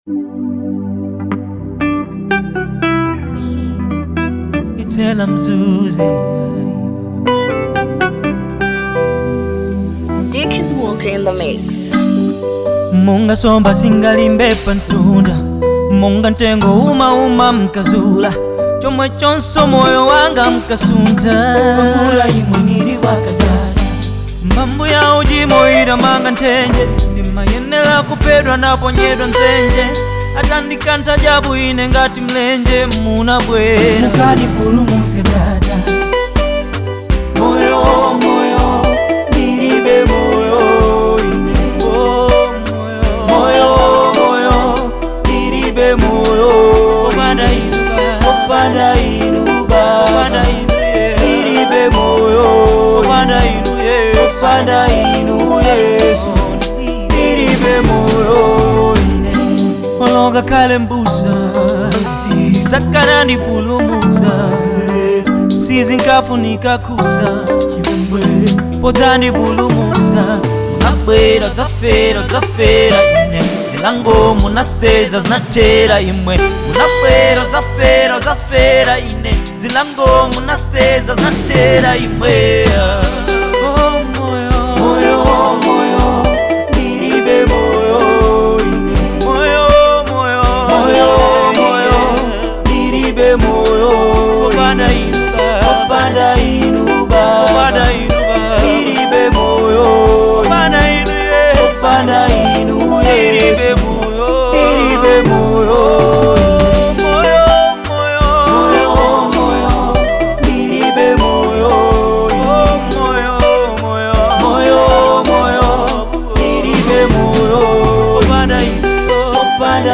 Genre : Afro soul